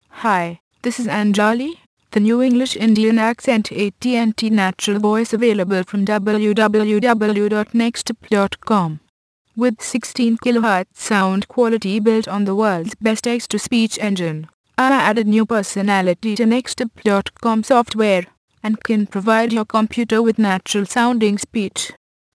Texte de d�monstration lu par Anjali (AT&T Natural Voices; distribu� sur le site de Nextup Technology; femme; anglais-am�ricain far west)